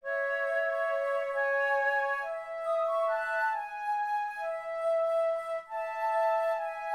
Witcha_Flute.wav